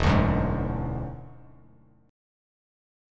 Listen to E9 strummed